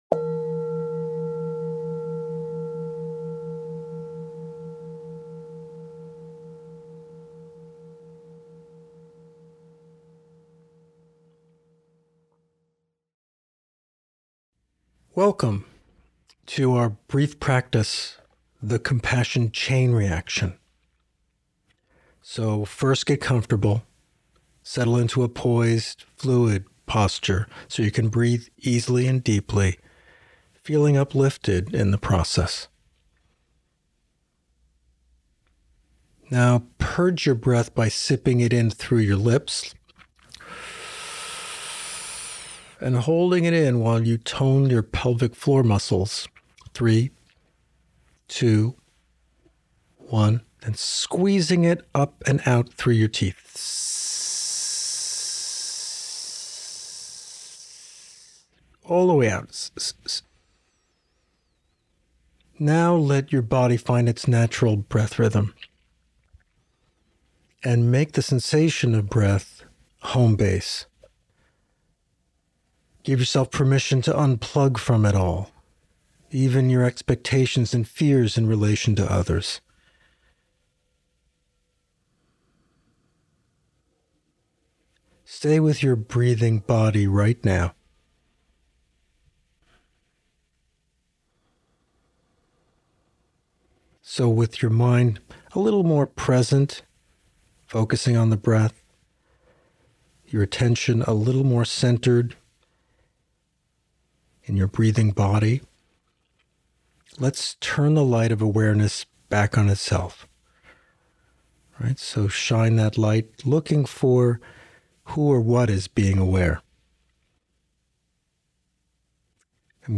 brief-practice-of-the-compassion-chain-reaction.mp3